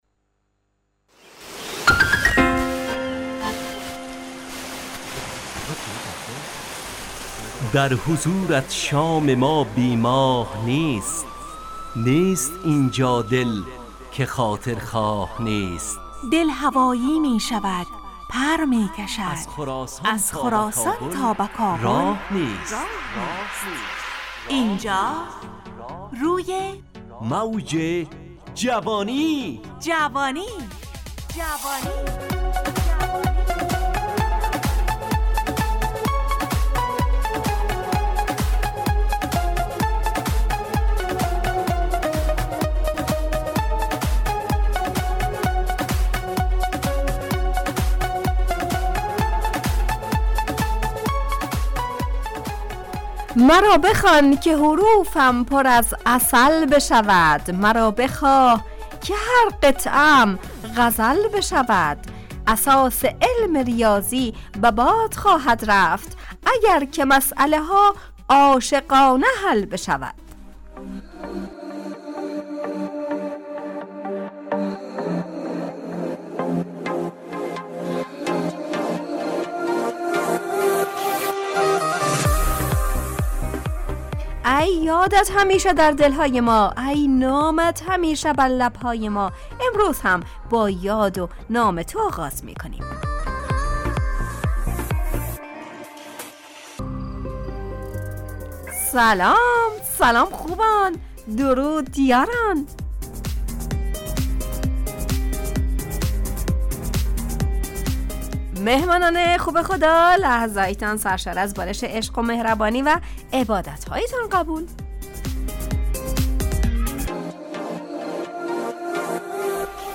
روی موج جوانی، برنامه شادو عصرانه رادیودری.
همراه با ترانه و موسیقی مدت برنامه 70 دقیقه . بحث محوری این هفته (قدرشناسی) تهیه کننده